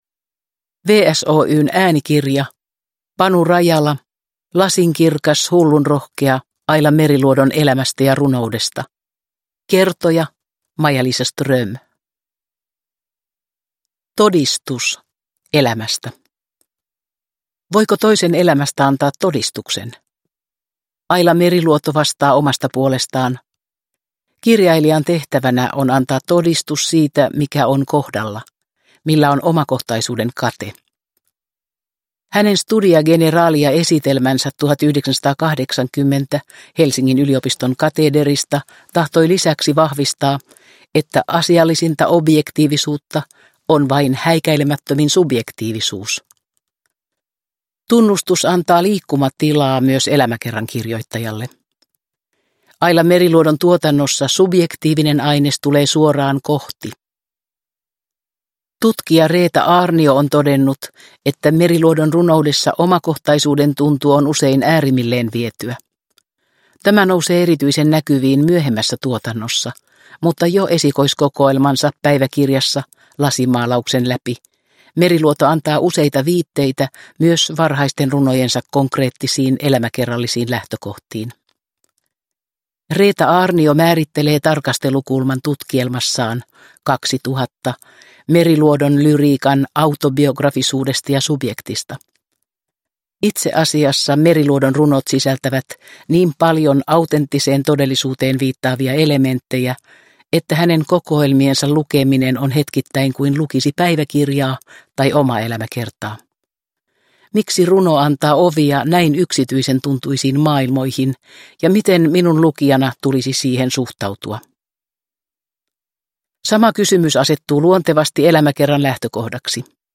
Lasinkirkas, hullunrohkea. – Ljudbok – Laddas ner